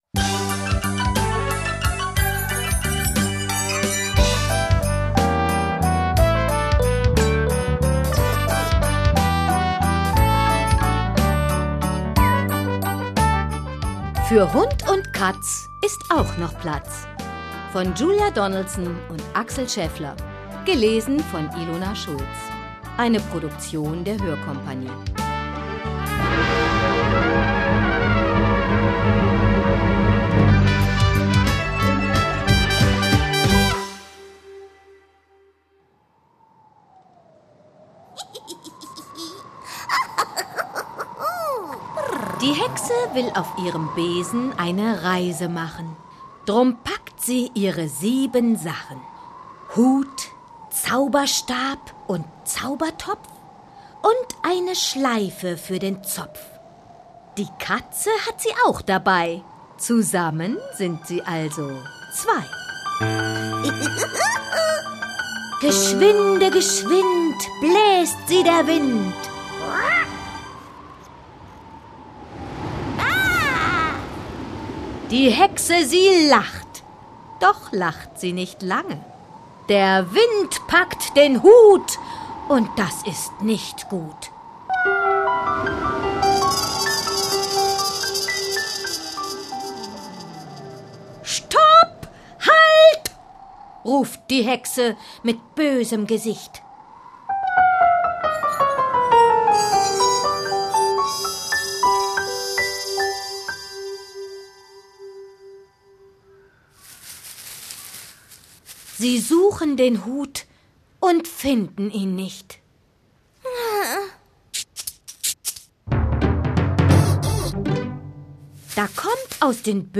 »Schon bei der Eingangsmelodie hellt sich die Miene auf.« hörBücher »Sie erweckt die Texte zum Leben, schlüpft in die aller kleinsten Rollen und füllt selbst diese nachhaltig aus.«